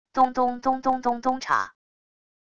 咚咚咚咚咚咚镲wav音频